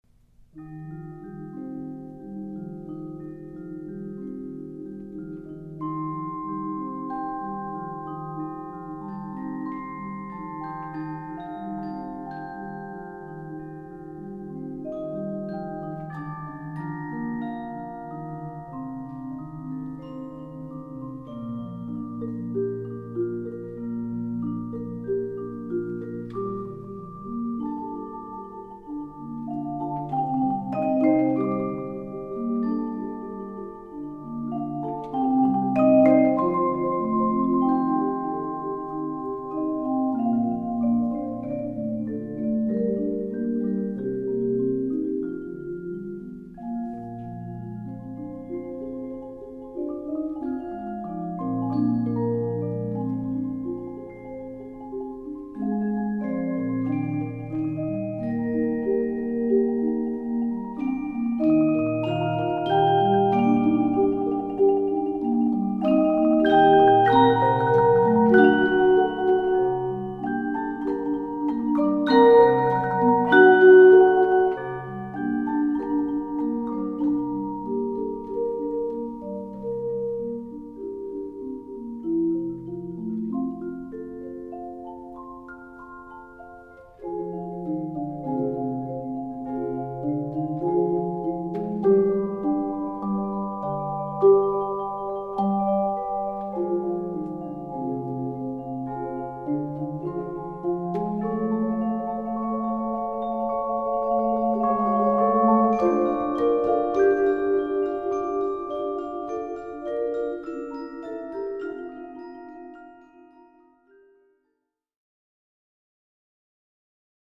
Voicing: Percussion Ensemble